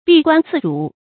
閉關自主 注音： ㄅㄧˋ ㄍㄨㄢ ㄗㄧˋ ㄓㄨˇ 讀音讀法： 意思解釋： 指關閉關口，不和外國來往。